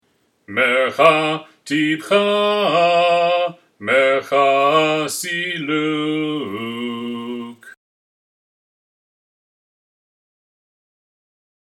TorahFinalSilluk.mp3